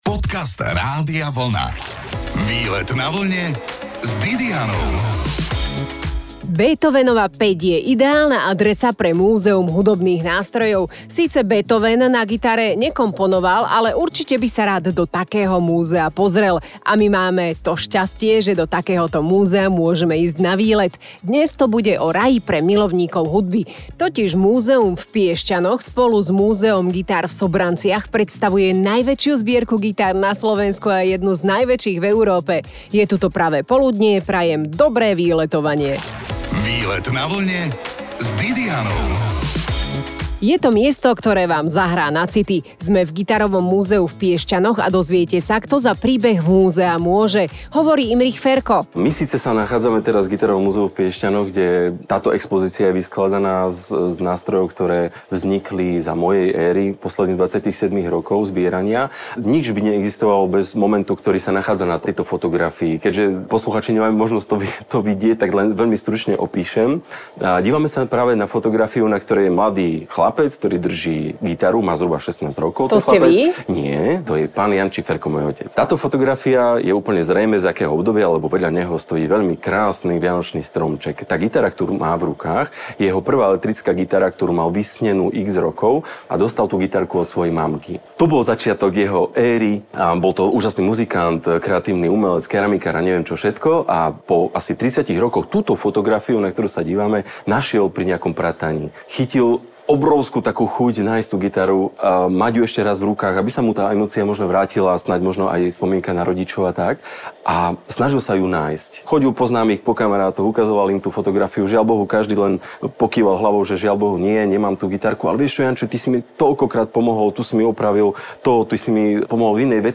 Výlet na Vlne je tentokrát z hravého Gitarového múzea v Piešťanoch.